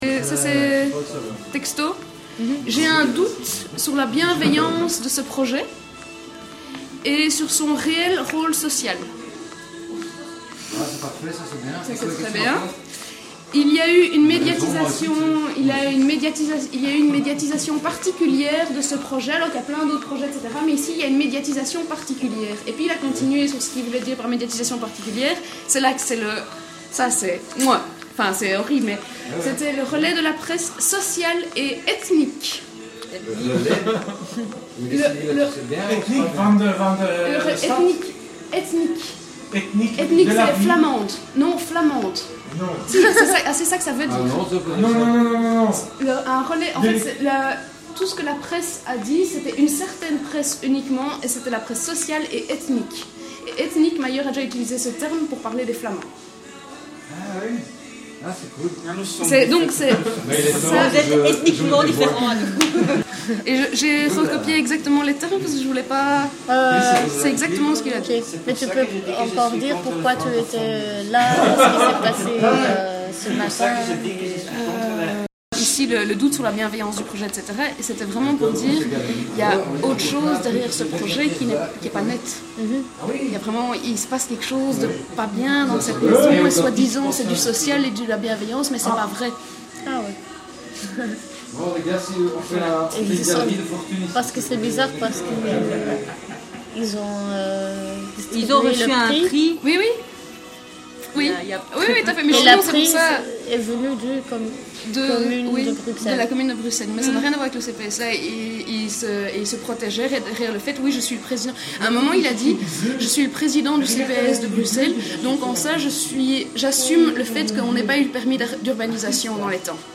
Een tiental activisten protesteerden dinsdag op de Grote markt voor het stadhuis van Brussel